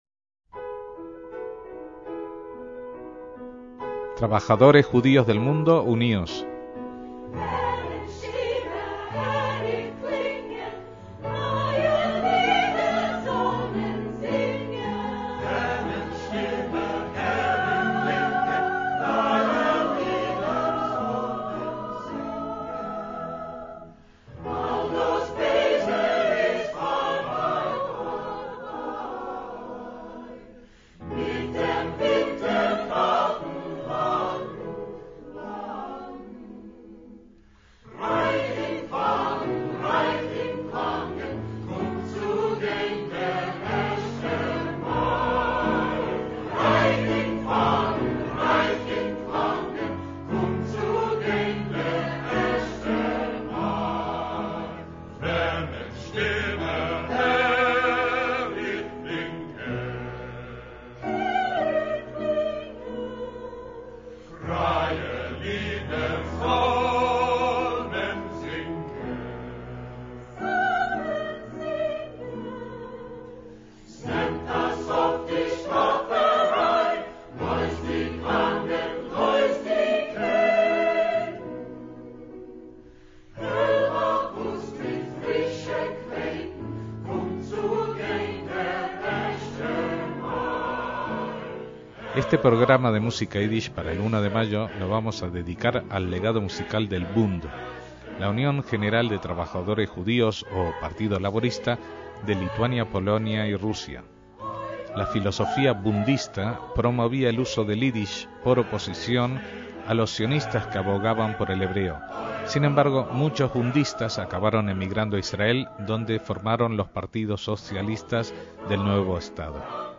MÚSICA ÍDISH